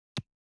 449リップクリーム,口紅,ふたを開ける,
効果音